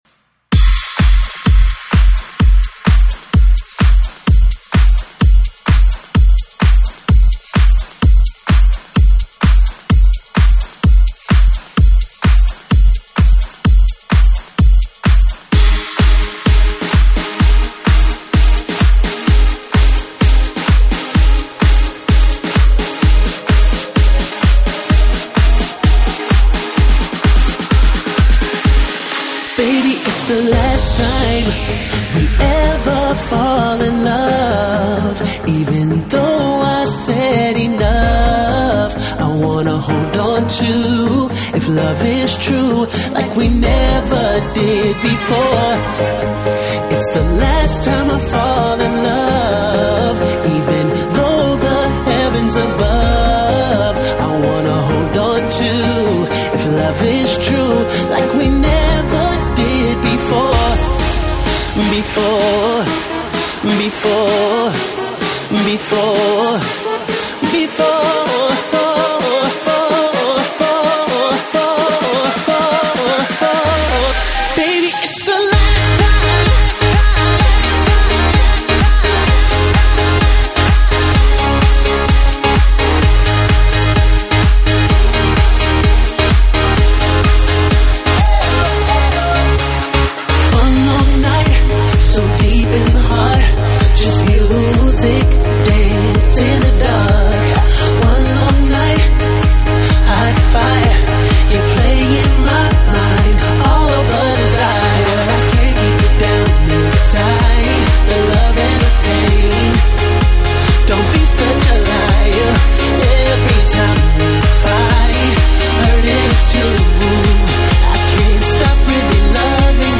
Genre: Dance, Pop |22Tracks | (百度盤)= 230 MB